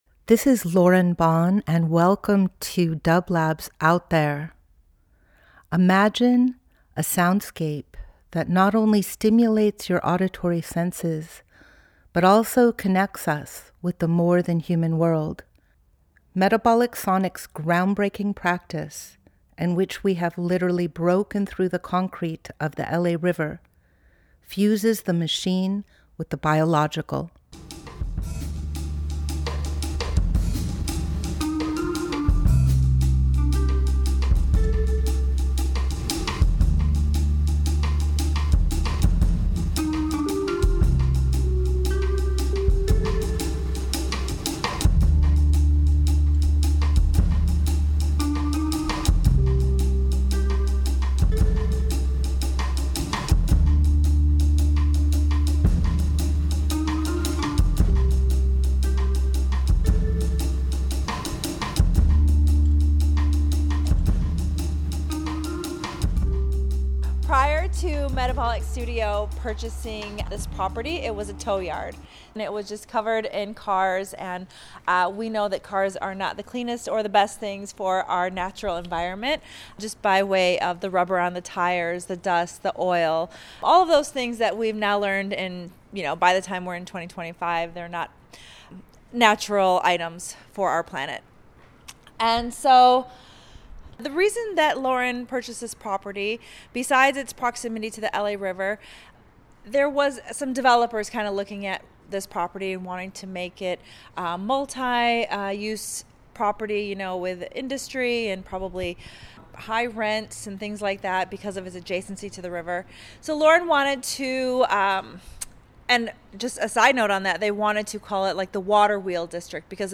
Each week we present a long-form field recording that will transport you through the power of sound.
Discussions on the Moon, 8-1-25 Part Two This is part of a community discussion about Metabolic Studio’s project Moving Mountains, which redistributes healthy topsoil from landslides in the art form Meandros. This discussion took place on August 1, 2025 at a tour of “The Moon”, a property that has been un-developed and is supporting native plant re-growth. The discussion is interspersed with improvised music by Metabolic Sonics.